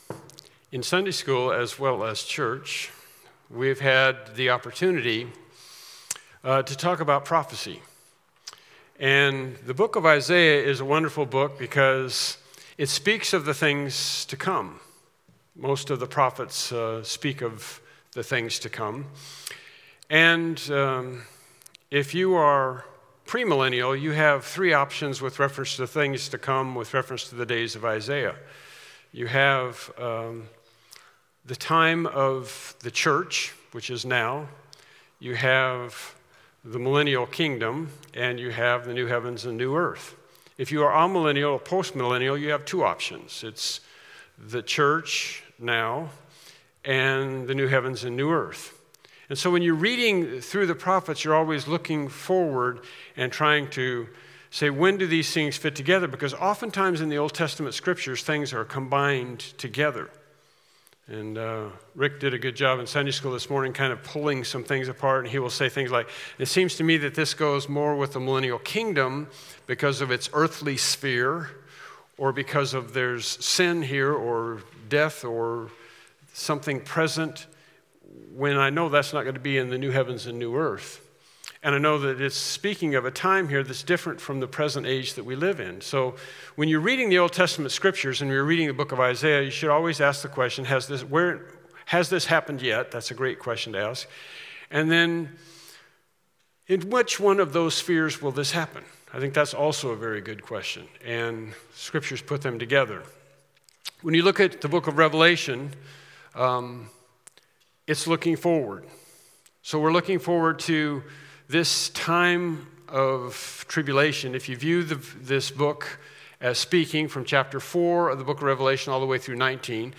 Revelation 16 Service Type: Evening Worship Service « “The Preeminent Savior” “Christ In You” »